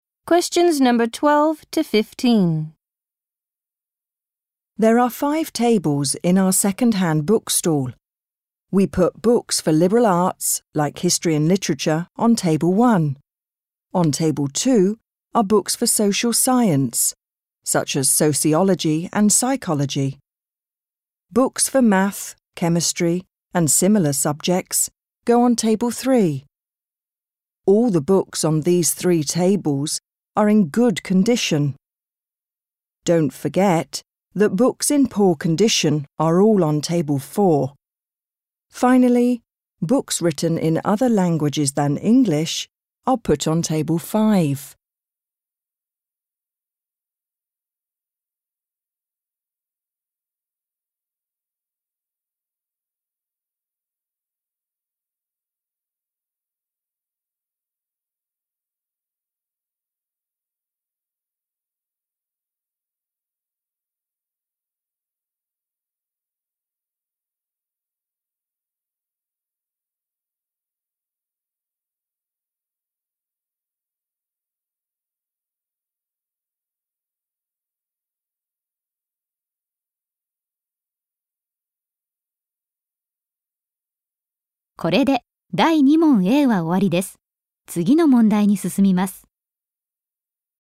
○共通テストの出題音声の大半を占める米英の話者の発話に慣れることを第一と考え，音声はアメリカ（北米）英語とイギリス英語で収録。
第3問形式：【第2回】第2問　問7 （アメリカ（北米）英語）